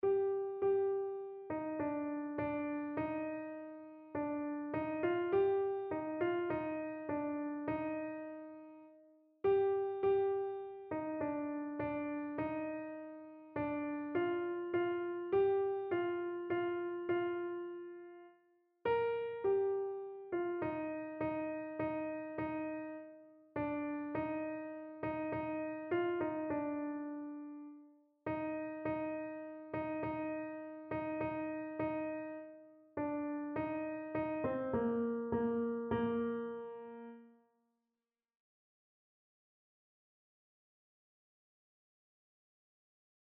Einzelstimmen (Unisono)